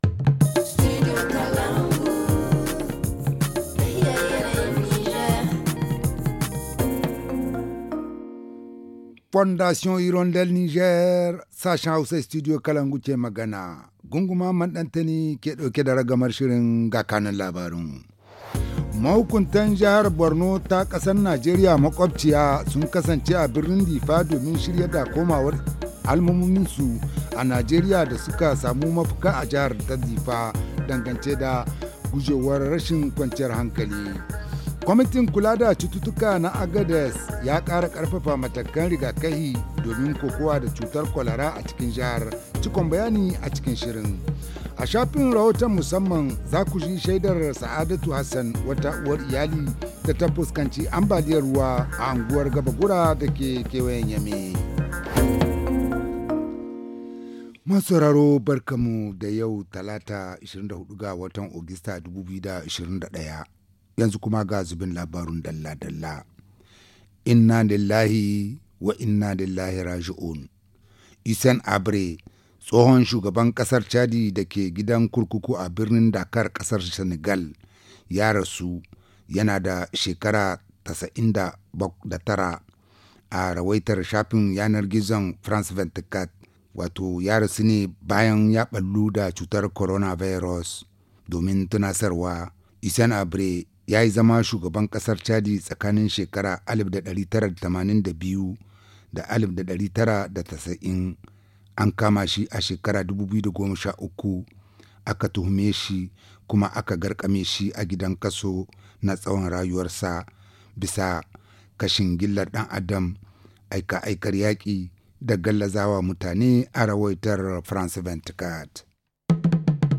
Le journal en français